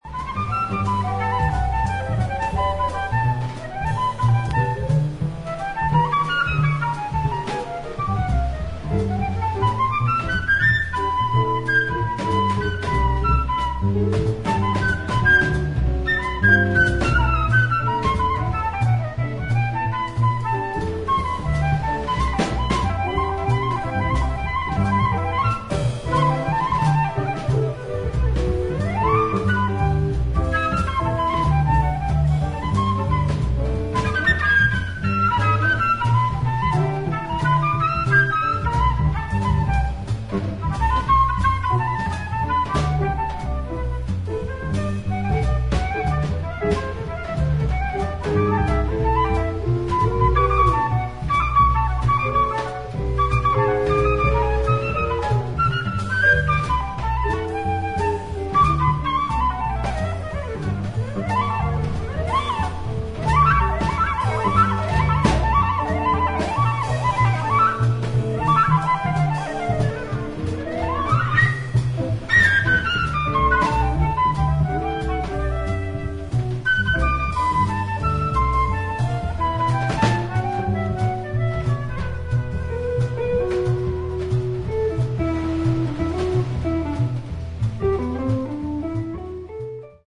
本作は、フェロー諸島にある断崖の渓谷の洞窟でレコーディングされたライヴ音源。